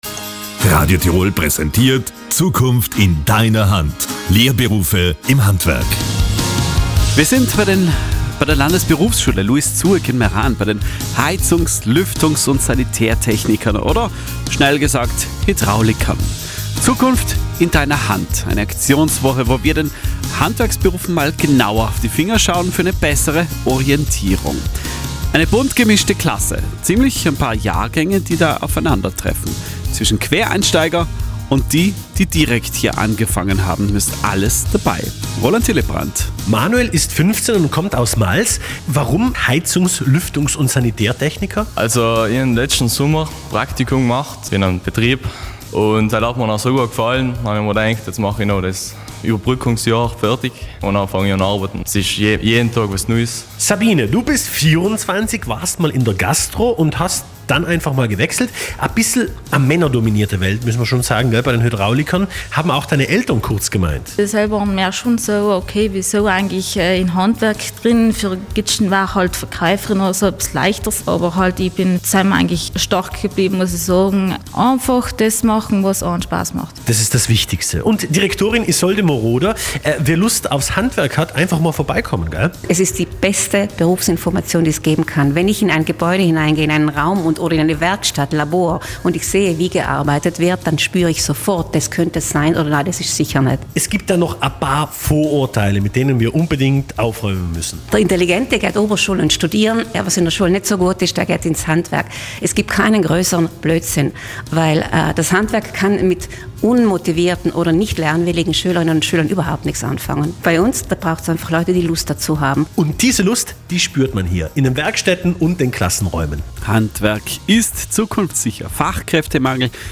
Interview Südtirol 1- Lehrlingsberufe im Handwerk